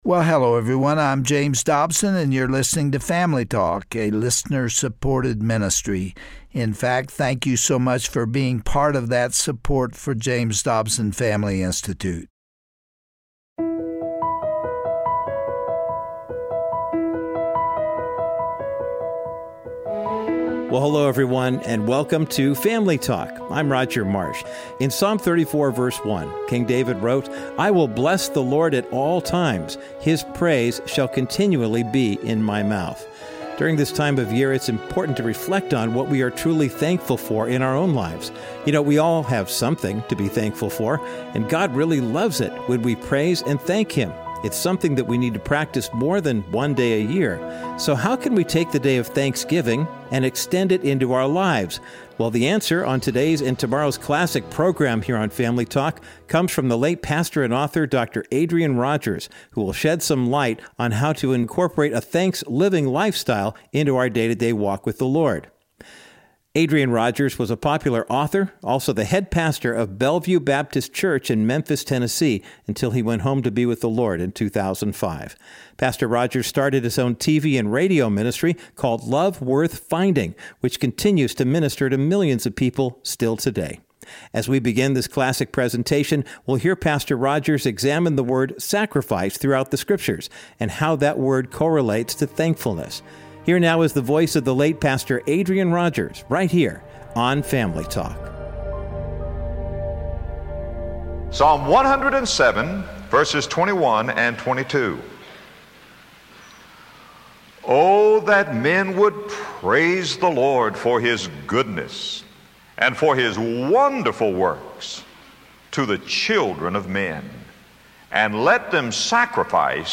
On today’s classic edition of Family Talk, we'll hear a special message